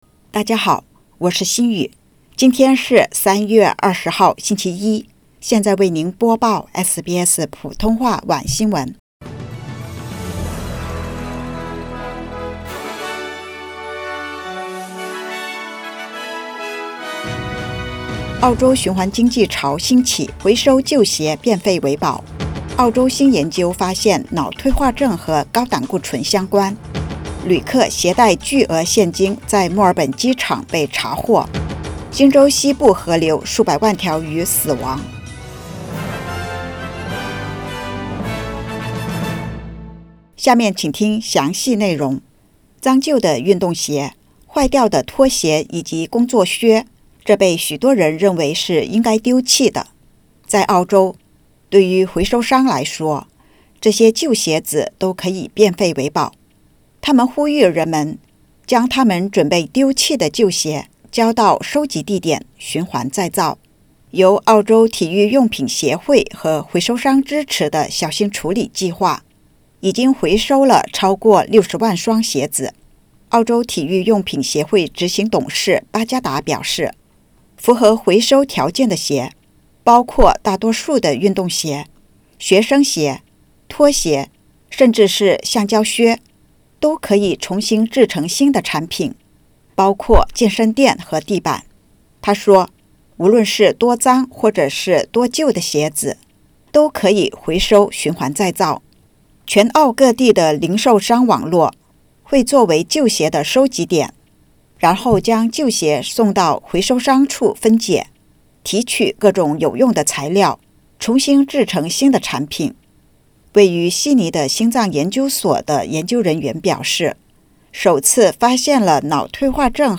SBS晚新闻（2023年3月20日）